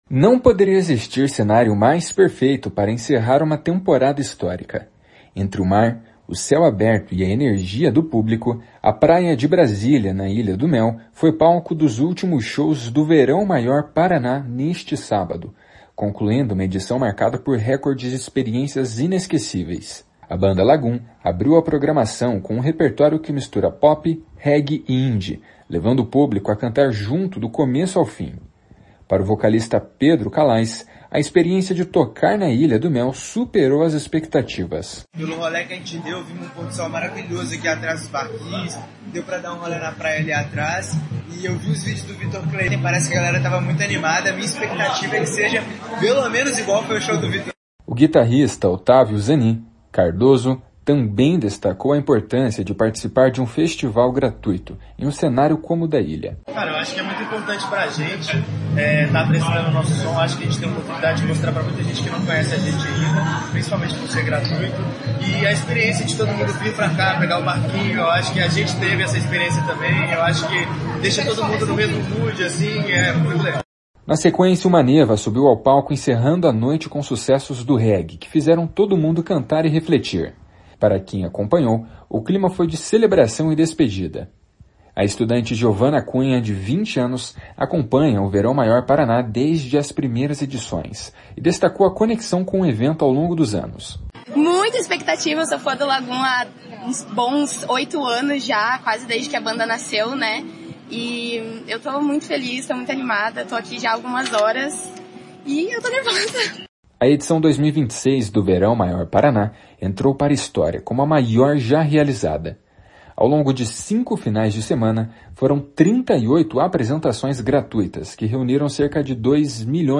DESPEDIDA NA ILHA SHOWS.mp3